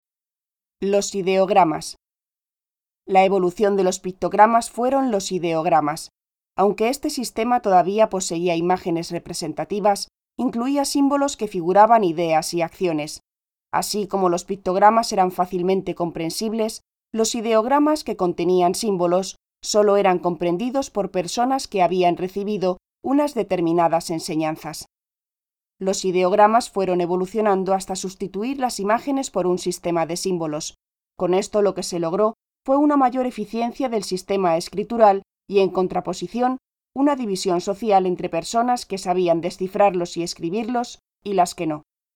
kastilisch
Sprechprobe: eLearning (Muttersprache):